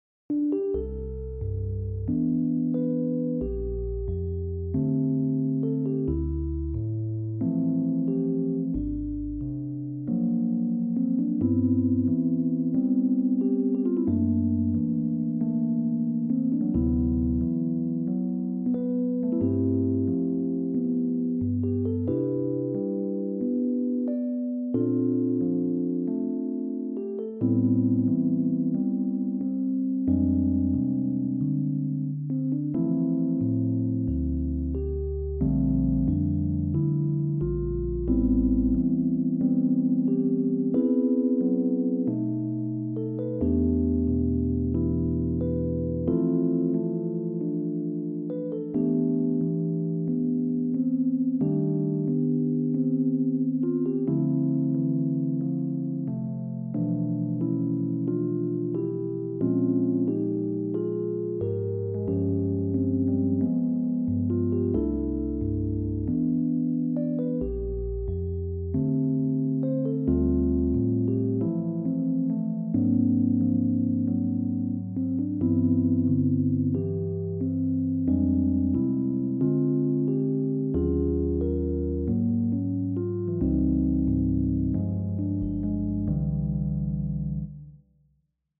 a few more original pieces for solo (jazz) piano
I was getting rather bored with nothing to do, so I thought I would do a bit more writing, even though the soundfiles have to be played by my music notation software.